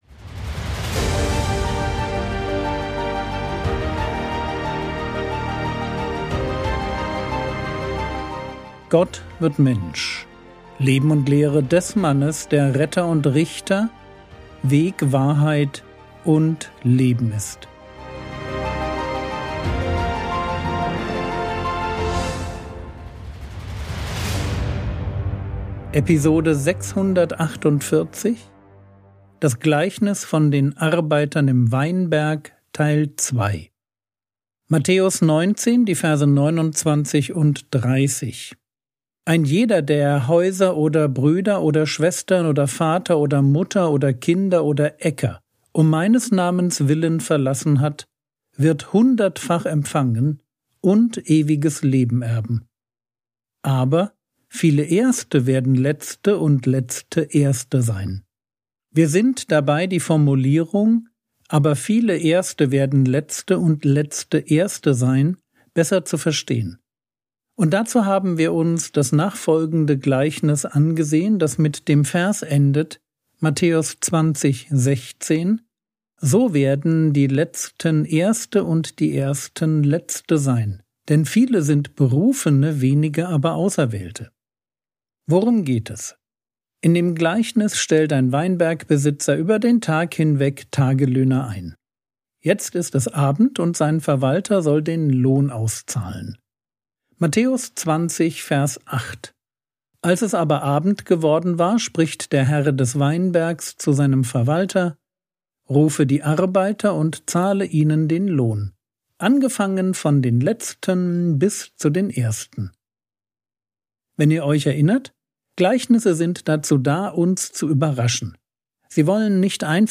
Episode 648 | Jesu Leben und Lehre ~ Frogwords Mini-Predigt Podcast